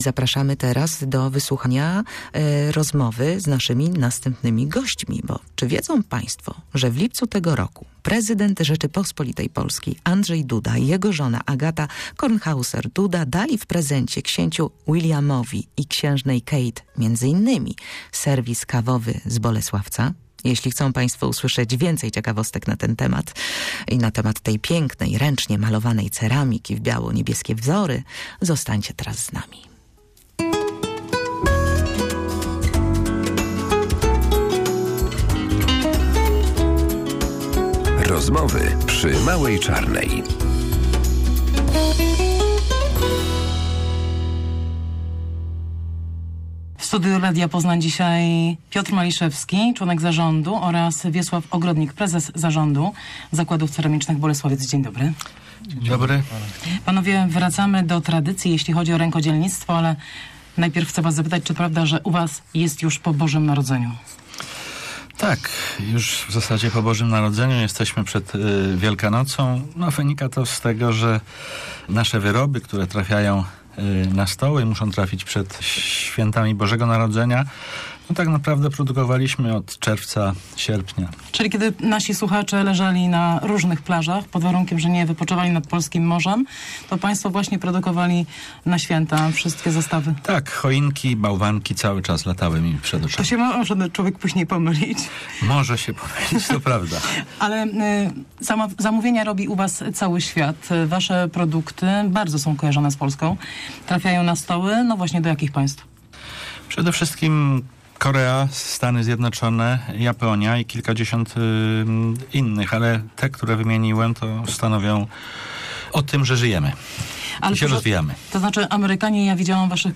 Dziś w „Małej Czarnej” rozmowa o ceramice – tej najbardziej znanej w Polsce i rozpoznawanej także poza jej granicami.